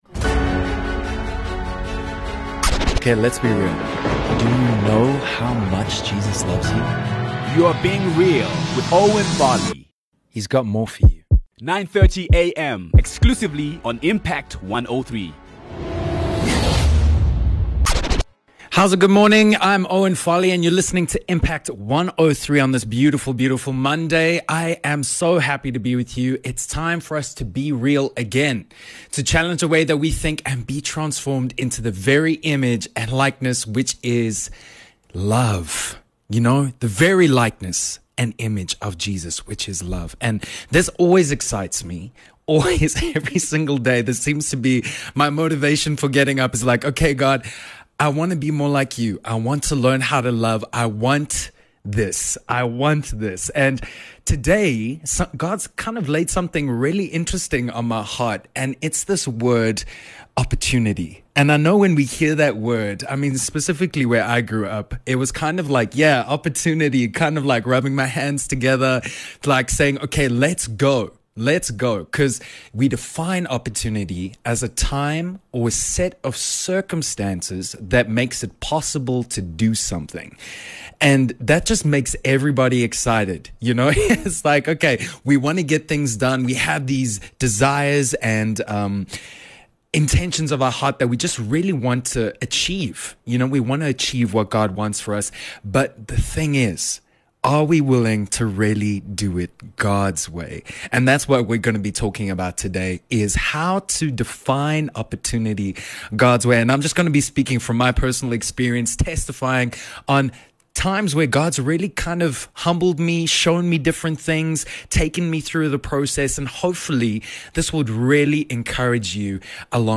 speaks from the heart on moments he has faced this and reads scripture to encourage us to be prepared to move through love!